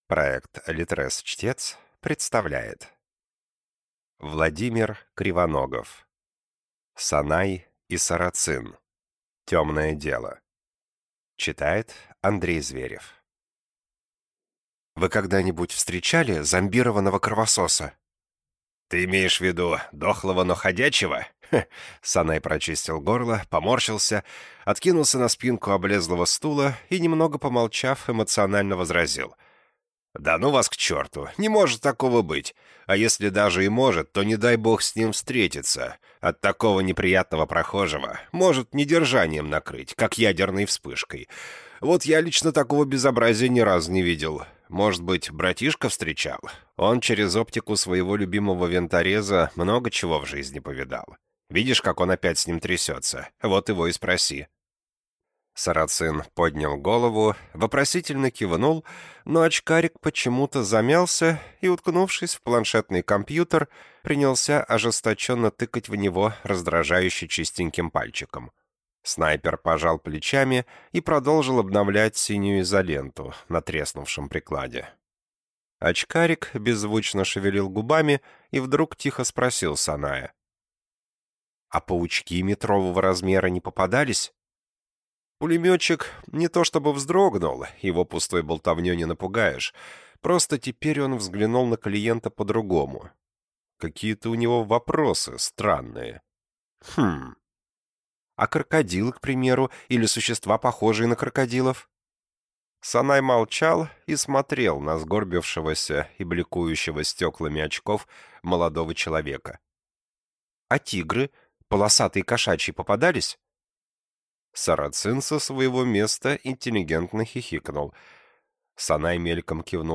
Аудиокнига Санай и Сарацин. Темное дело | Библиотека аудиокниг